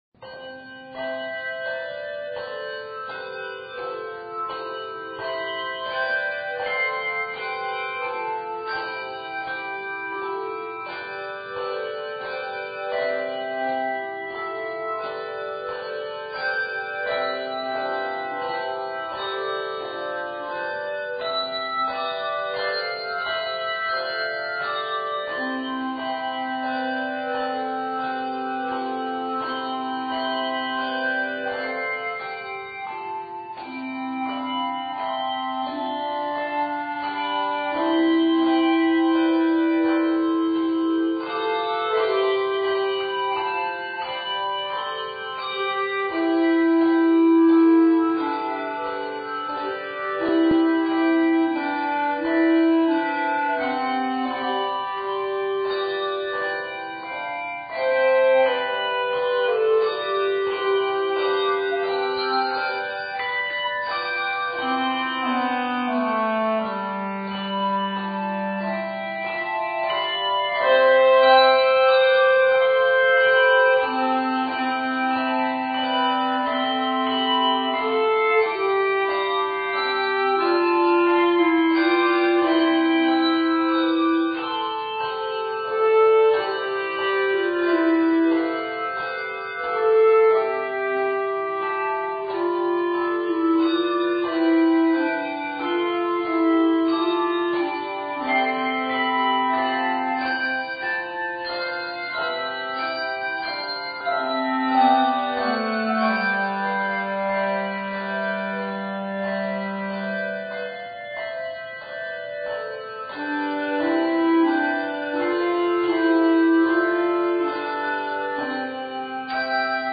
Bb Clarinet and 3-octaves of bells as accompaniment